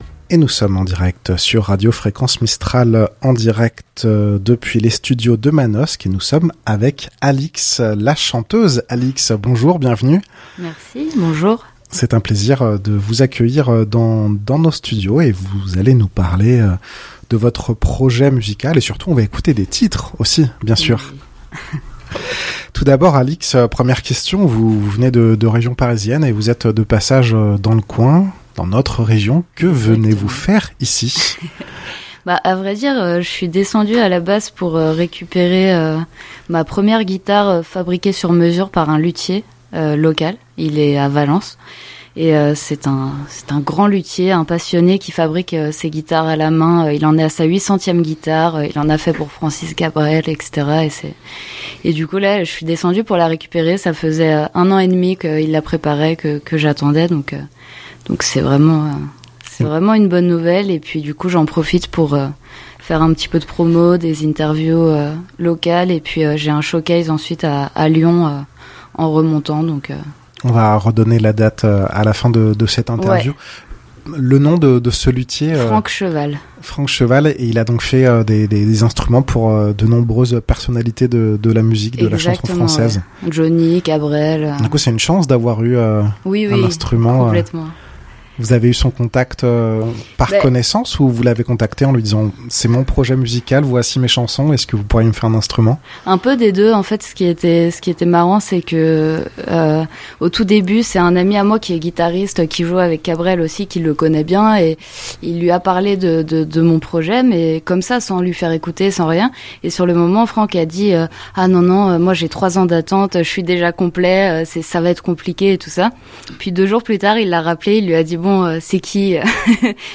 dans les studios de Fréquence Mistral à Manosque en direct lors du Magazine de Midi, pour nous parler de sa musique, de ses textes, de ses projets actuels et futurs... Interview